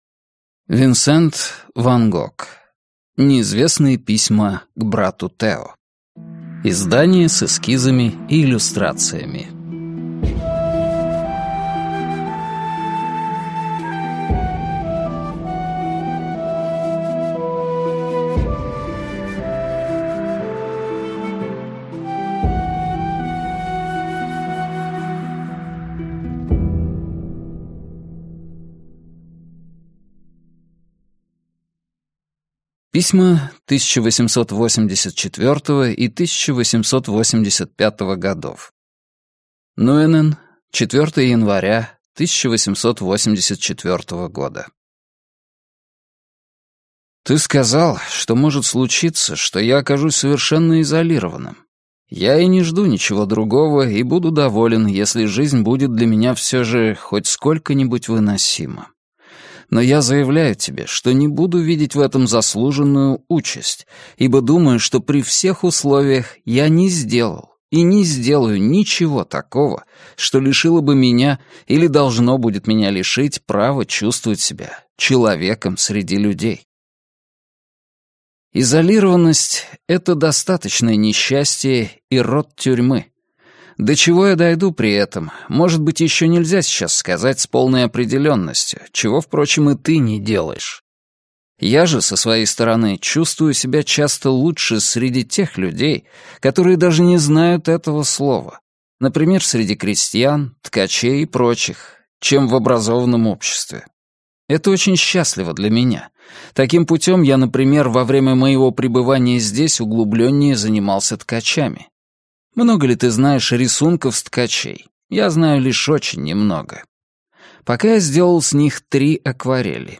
Аудиокнига Неизвестные письма к брату Тео | Библиотека аудиокниг